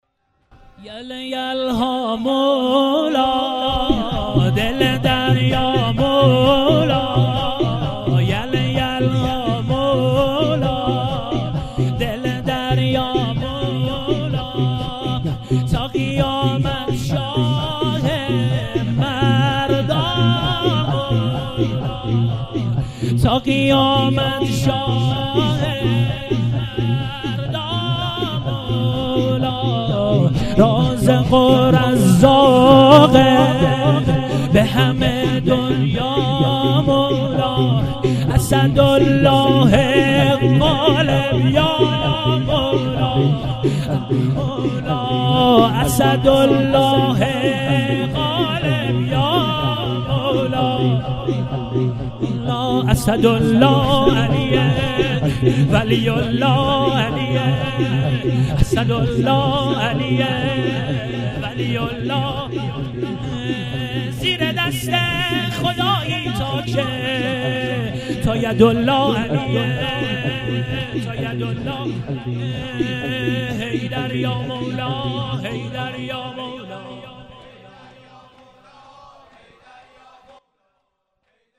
جلسات هفتگی
شور احساسی